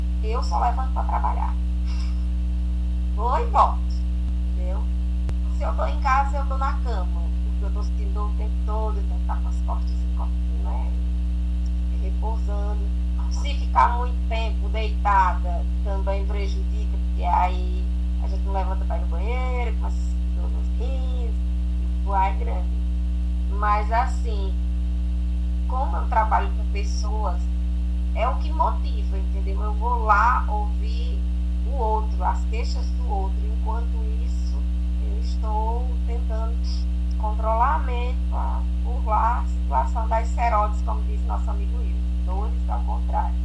Depoimento completo